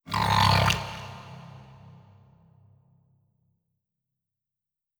khloCritter_Male21-Verb.wav